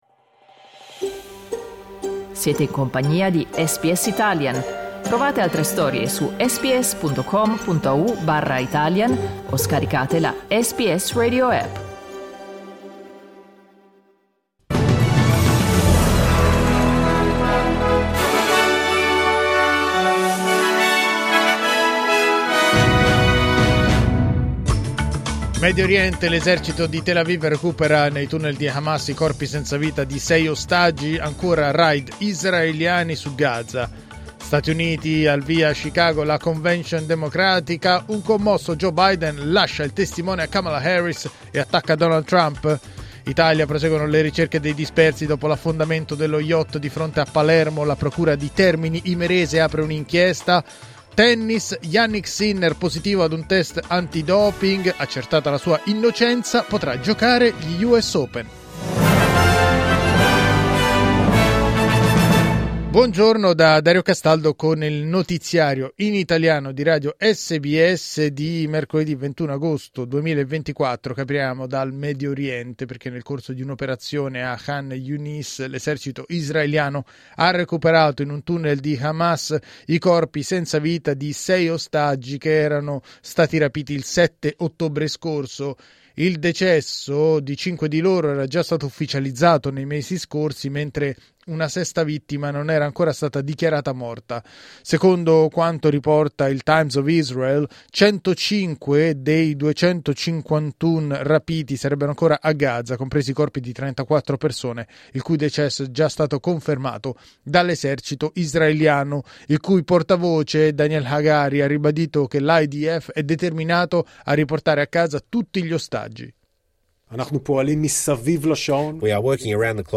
Giornale radio mercoledì 21 agosto 2024
Il notiziario di SBS in italiano.